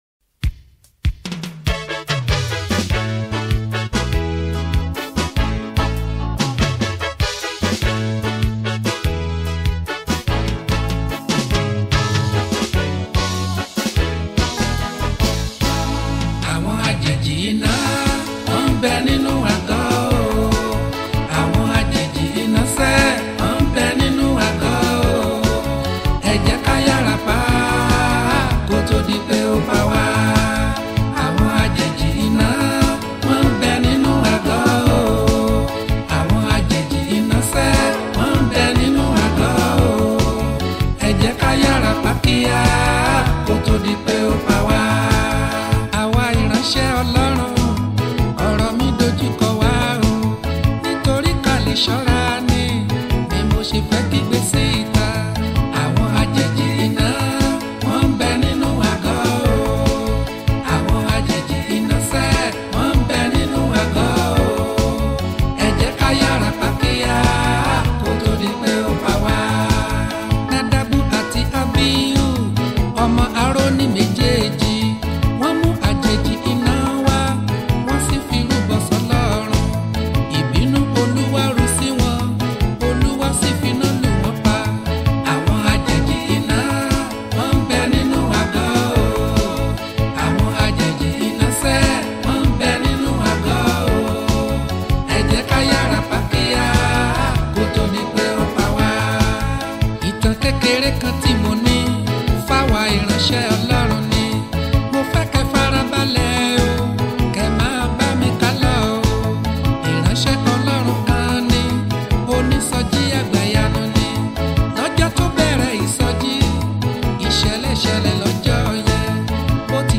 March 28, 2025 Publisher 01 Gospel 0